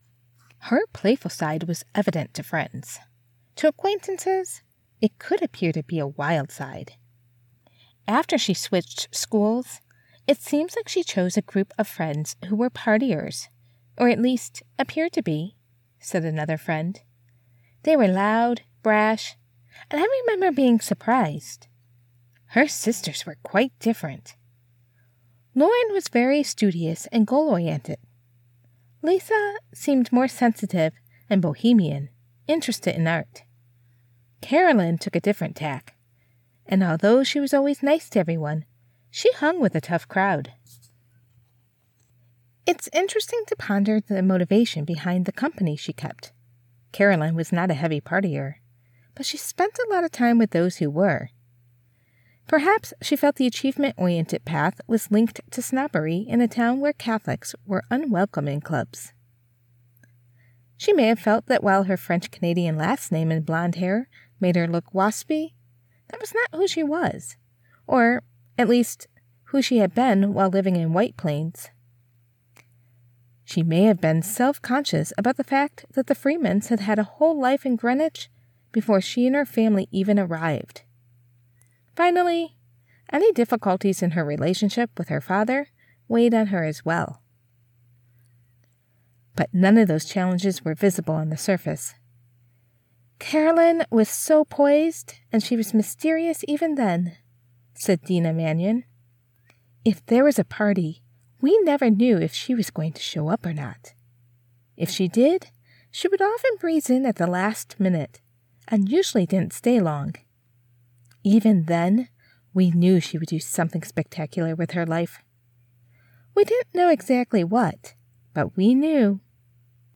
Home Studio Specs: AT2020USB+ Cardioid Condenser USB Mic, Audacity, DropBox or WeTransfer.
Biography | 3rd | 3x F | Warm, Observational, Lightly Gossipy
Warm, Grounded, Midwest accent
Clear and intuitive